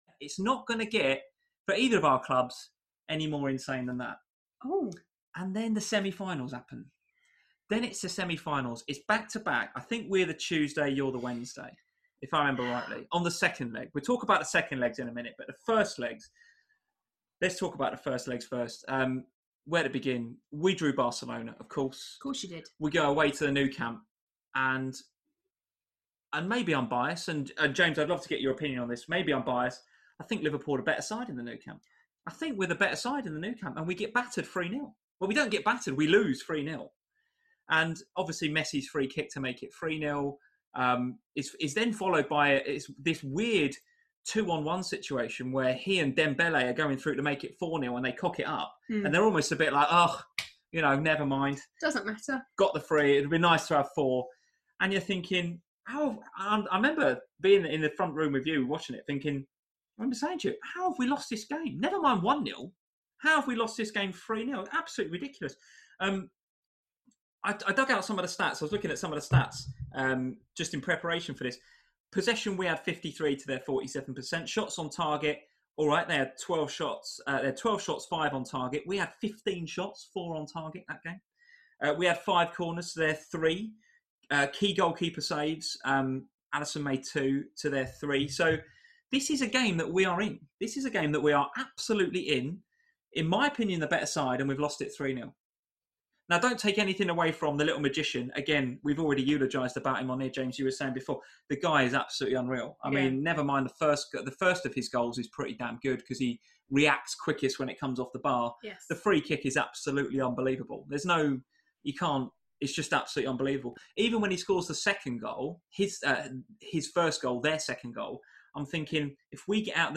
Welcome to FPL Family, a chat show dedicated to all things Fantasy Premier League.